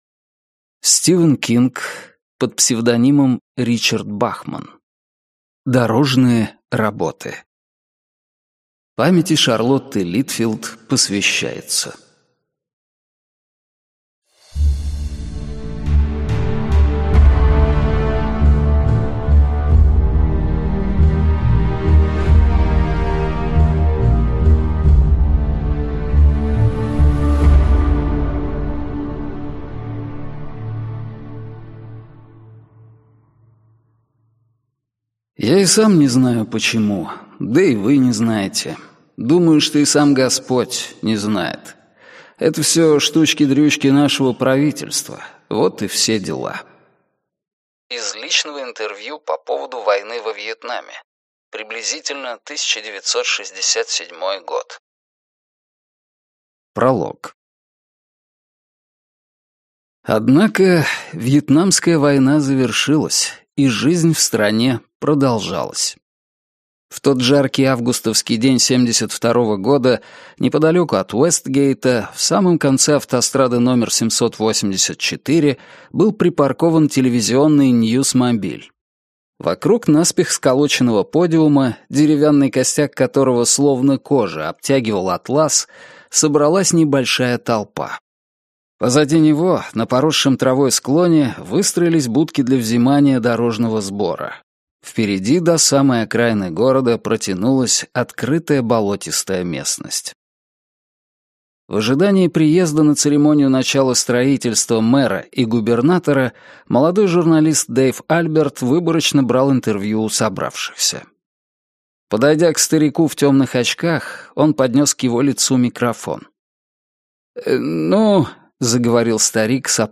Аудиокнига Дорожные работы - купить, скачать и слушать онлайн | КнигоПоиск
Аудиокнига «Дорожные работы» в интернет-магазине КнигоПоиск ✅ Зарубежная литература в аудиоформате ✅ Скачать Дорожные работы в mp3 или слушать онлайн